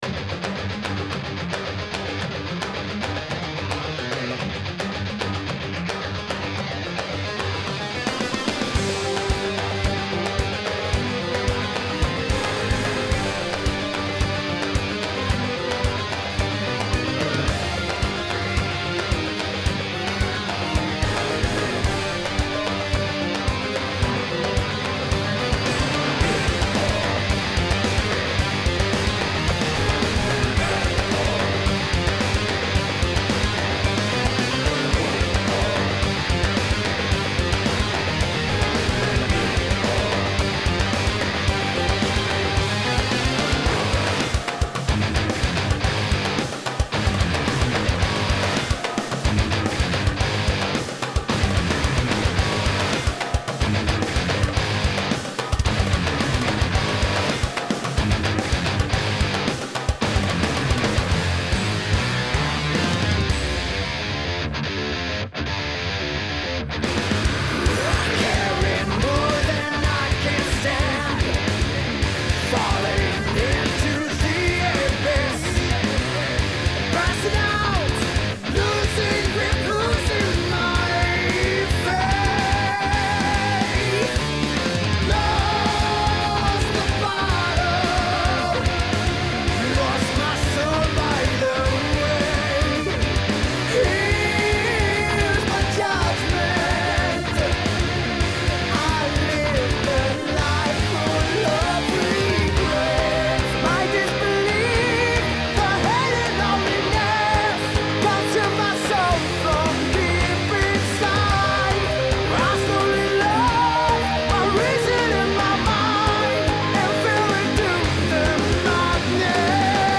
Progressive metal